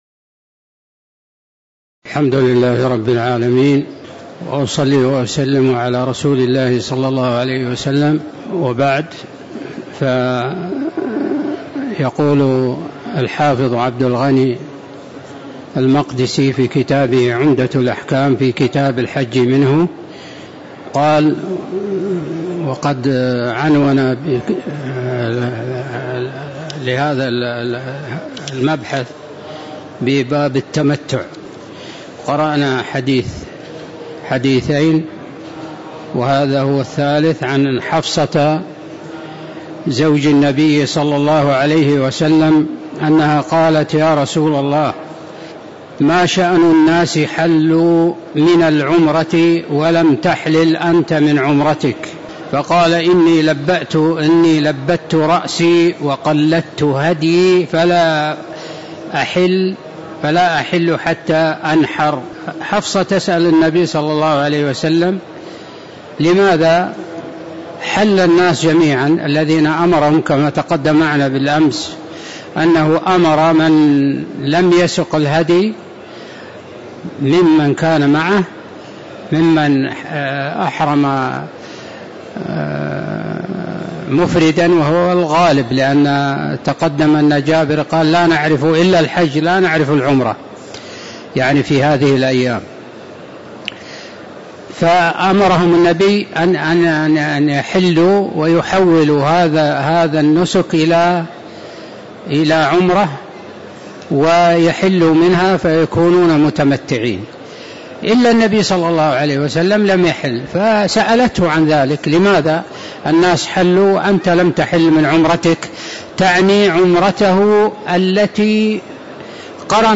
تاريخ النشر ٤ ذو الحجة ١٤٤٣ هـ المكان: المسجد النبوي الشيخ